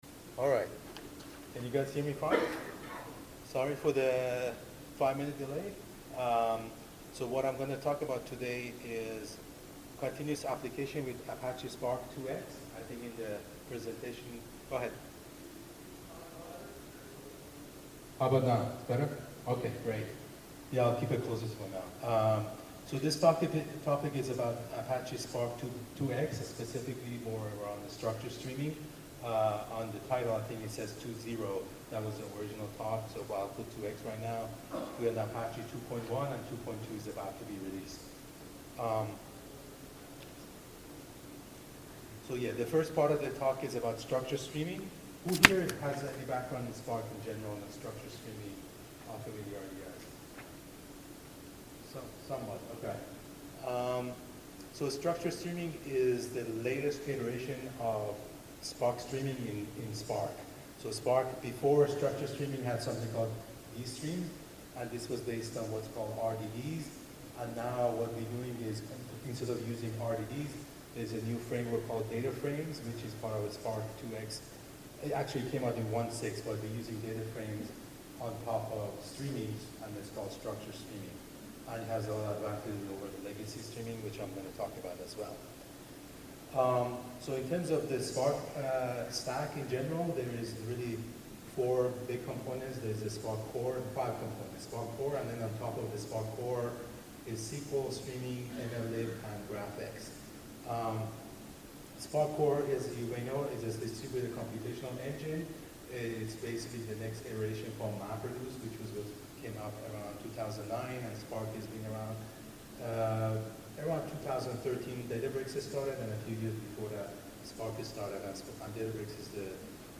ApacheCon Miami 2017 – Continuous Applications with Apache Spark 2.0